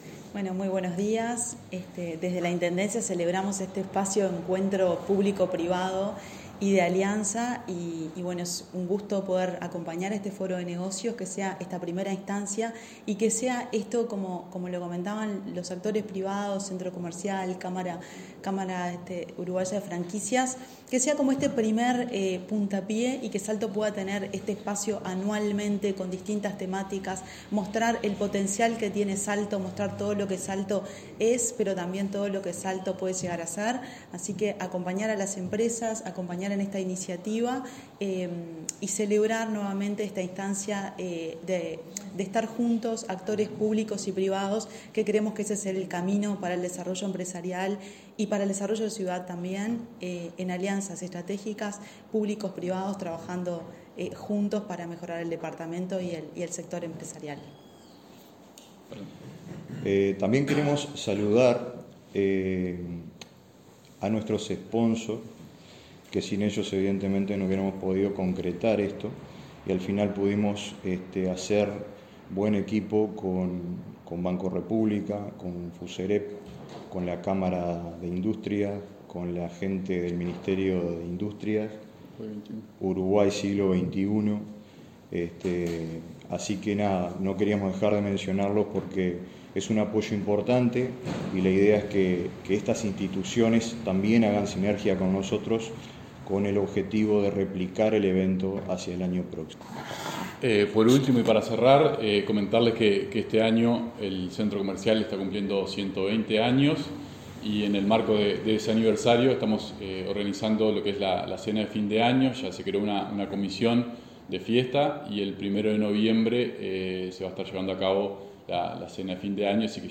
Este martes 29 de junio se realizó la conferencia de prensa de lanzamiento del Foro de Negocios Salto 2025, que se llevará a cabo el próximo 12 de agosto en el Centro Comercial e Industrial.